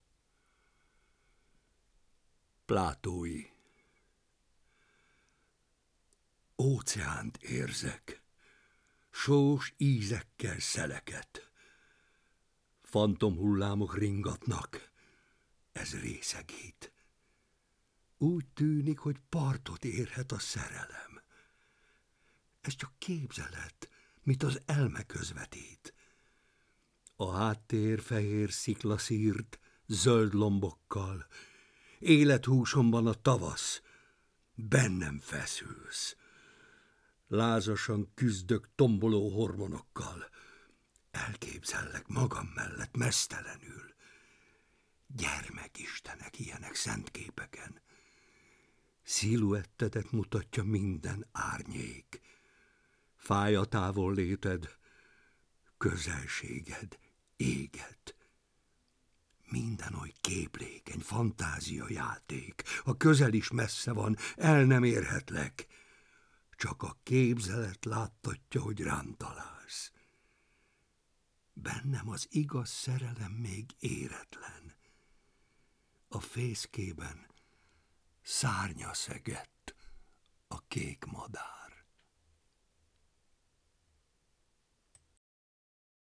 *Versolvasó: Barbinek Péter